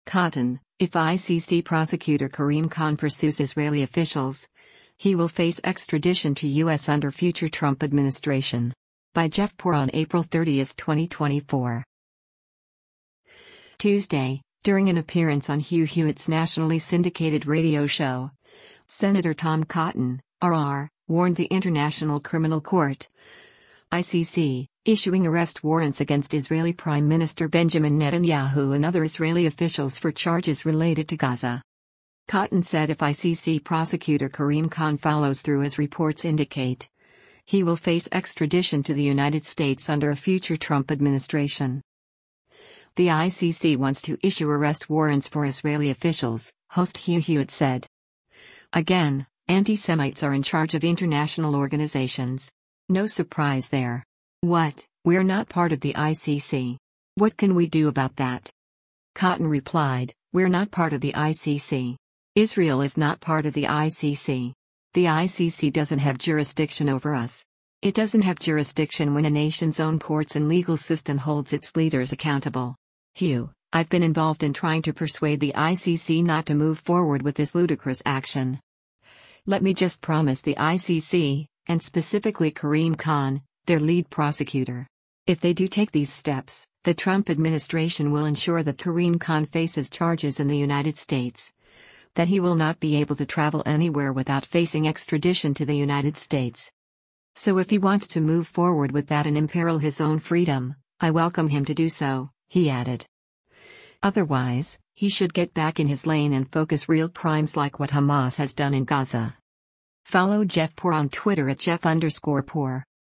Tuesday, during an appearance on Hugh Hewitt’s nationally syndicated radio show, Sen. Tom Cotton (R-AR) warned the International Criminal Court (ICC) issuing arrest warrants against Israeli Prime Minister Benjamin Netanyahu and other Israeli officials for charges related to Gaza.